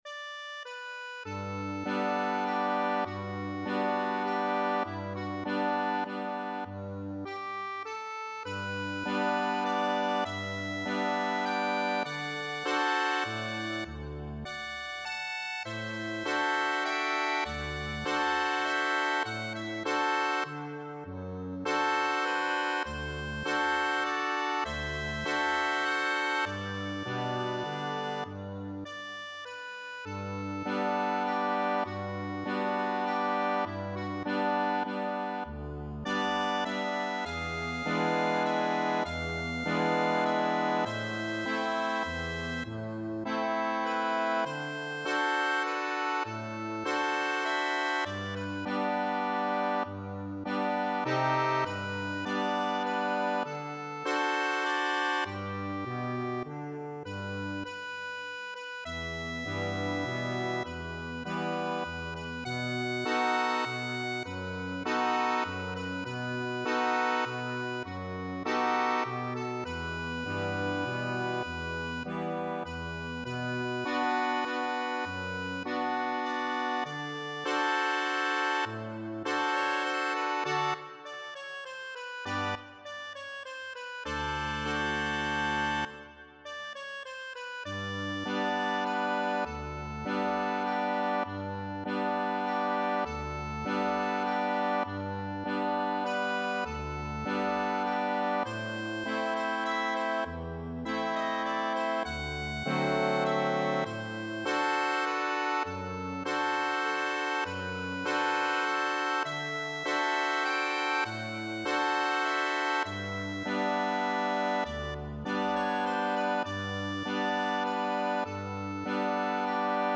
Accordion version
merry Italian ballad, originally in three-quarter time
3/4 (View more 3/4 Music)
Accordion  (View more Intermediate Accordion Music)
Classical (View more Classical Accordion Music)